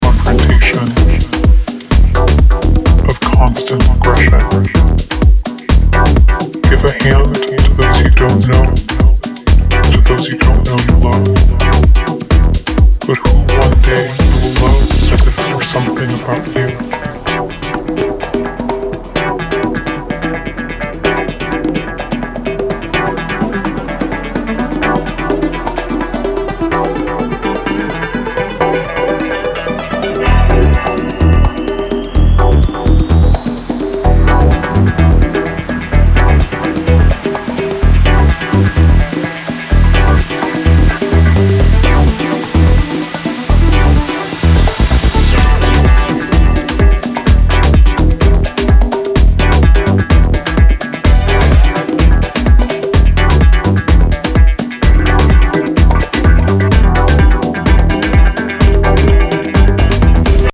• Afro House